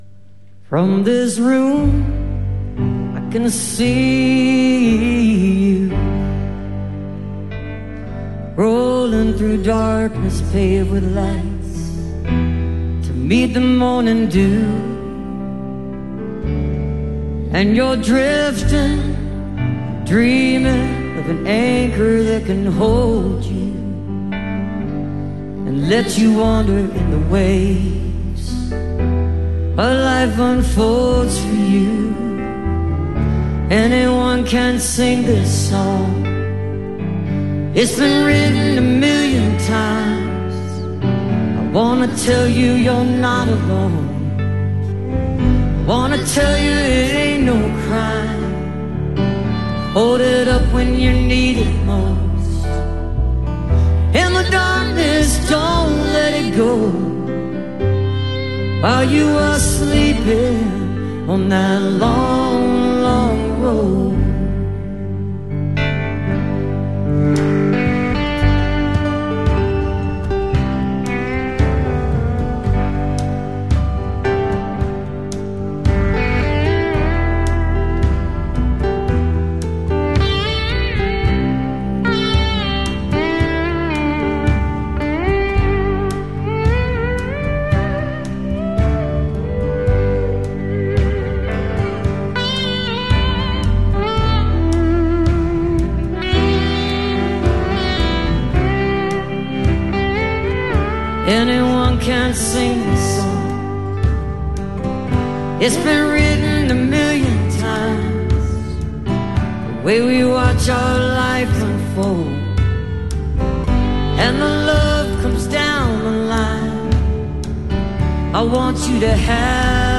(captured from the live stream)